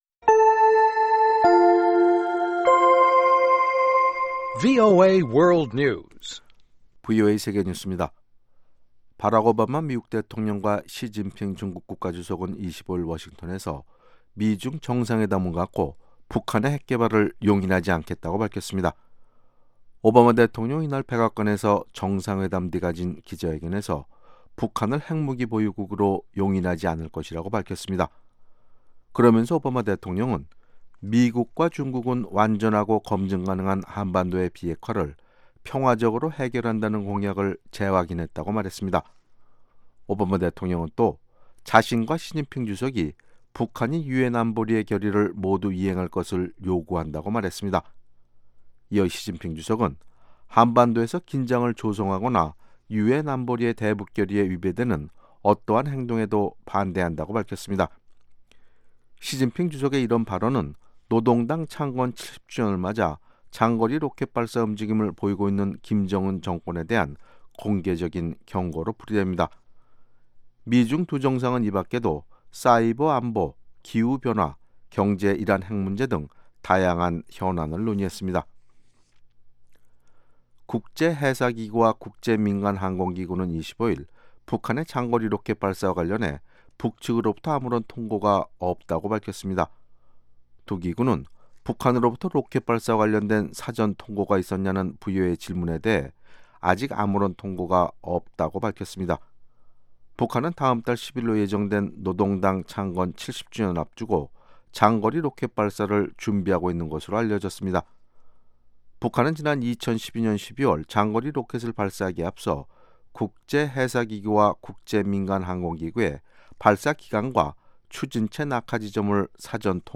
VOA 한국어 방송의 아침 뉴스 프로그램 입니다. 한반도 시간 매일 오전 4시부터 5시까지 방송됩니다.